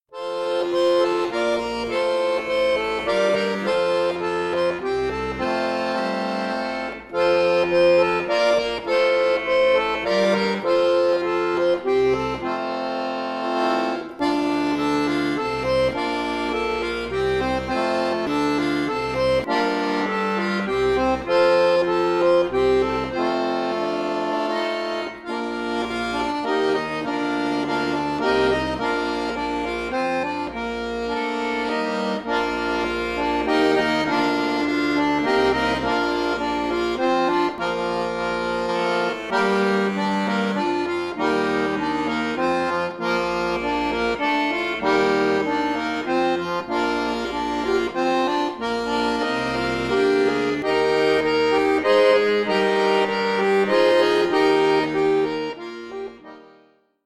Schwungvolles Traditional zur Einstimmung auf Weihnachten
Akkordeon Solo
Weihnachtslied